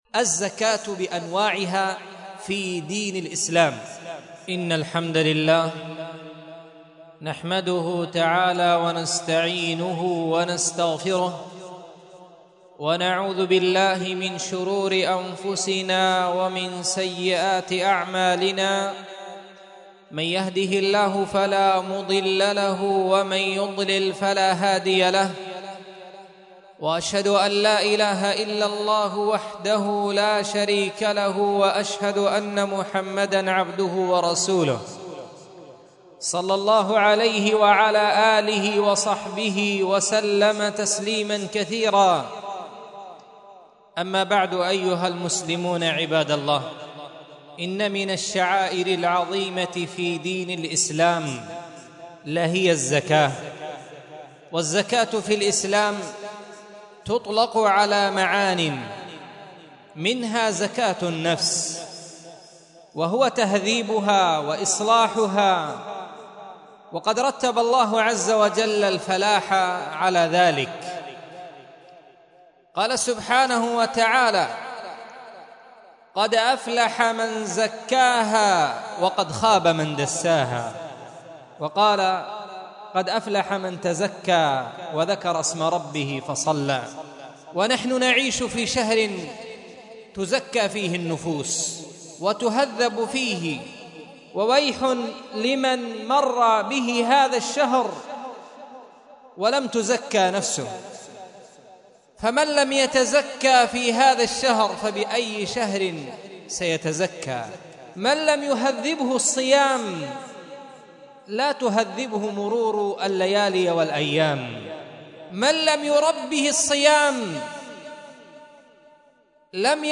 مسجد درة عدن محافظة عدن حرسها الله